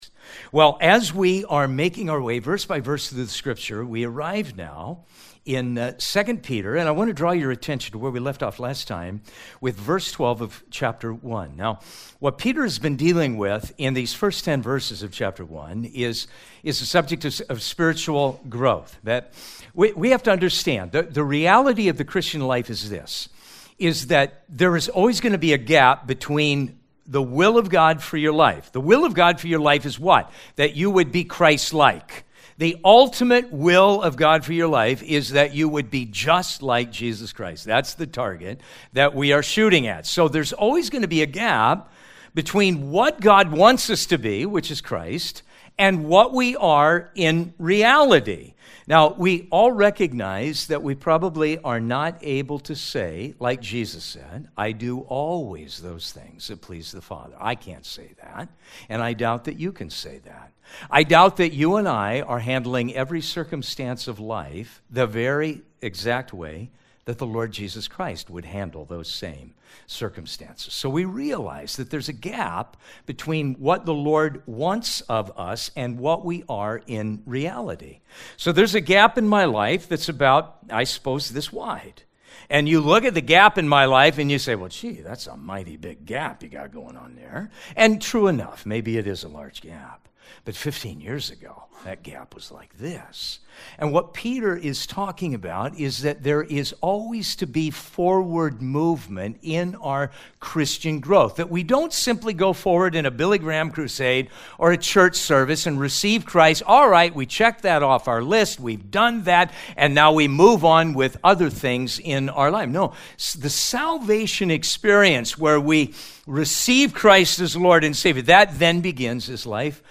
We feature verse by verse teachings through the Bible, topical messages, and updates from the staff and lead team.